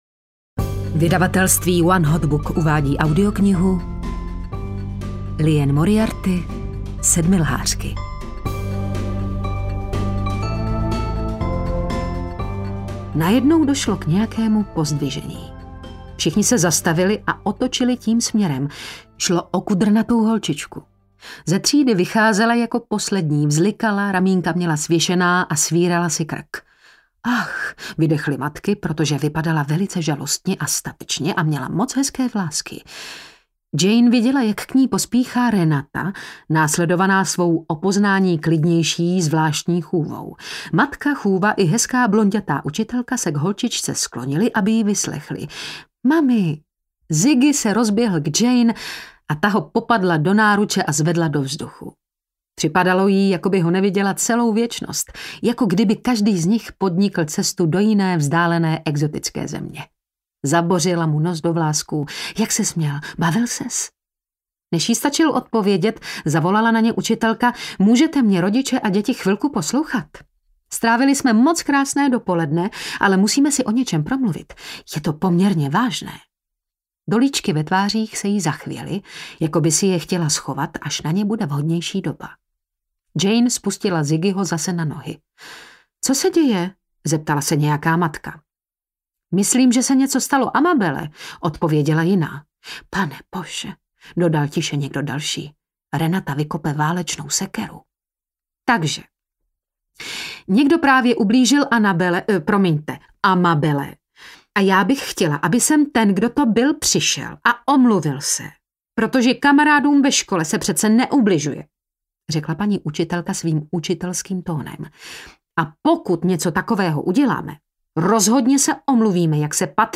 Sedmilhářky audiokniha
Ukázka z knihy
• InterpretTereza Bebarová